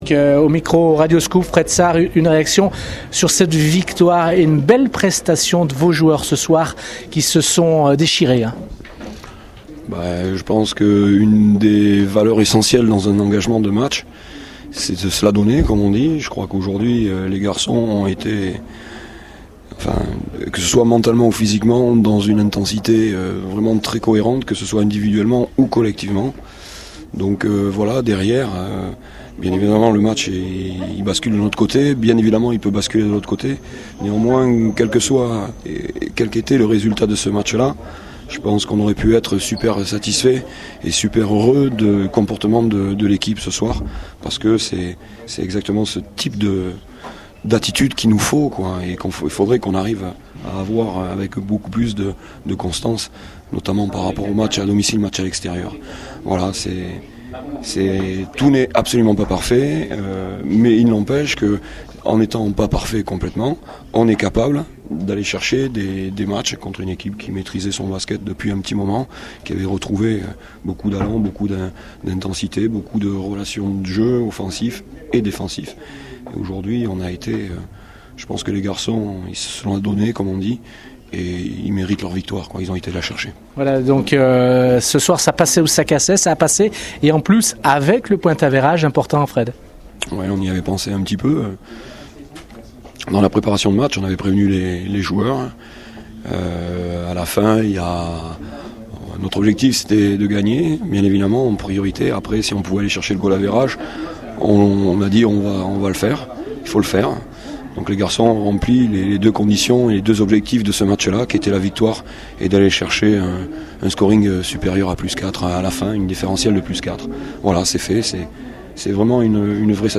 réactions d’après-match